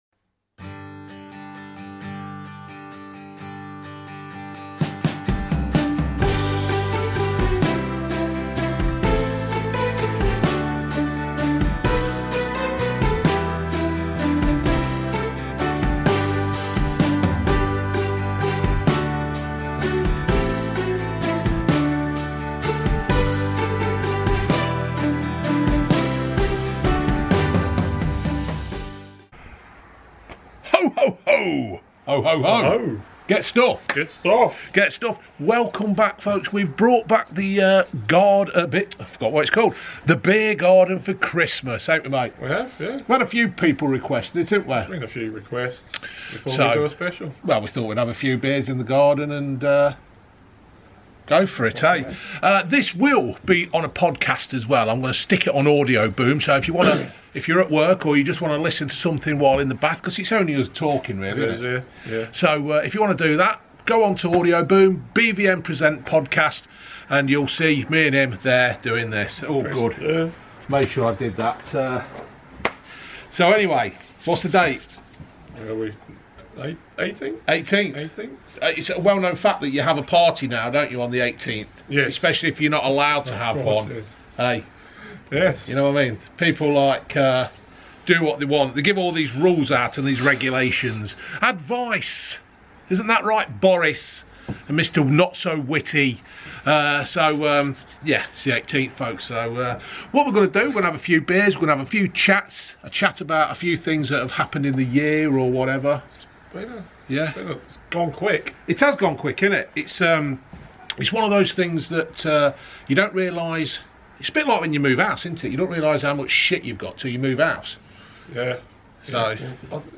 just chatting like you would in a pub or a Beer Garden